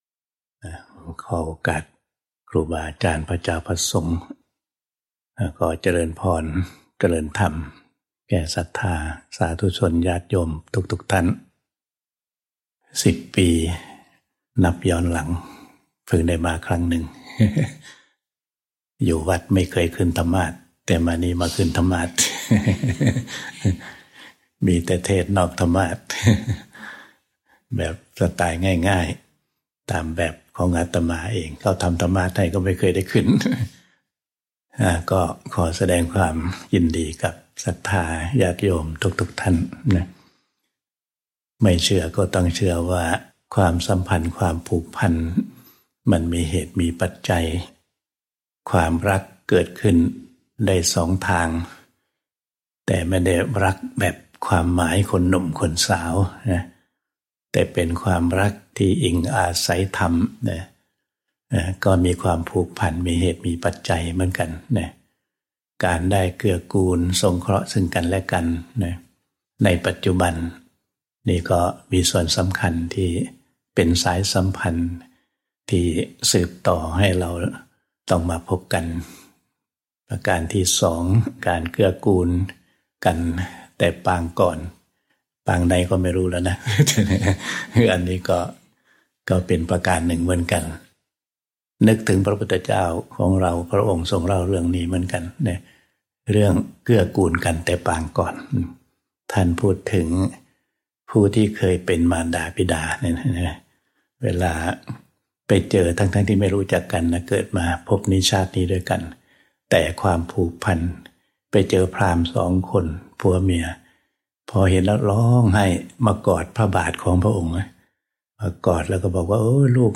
1 It’s How We Hold Things Play Pause 1d ago Play Pause Play later Play later Lists Like Liked — This Dhamma talk was offered on May 26, 2025 at Abhayagiri Buddhist Monastery.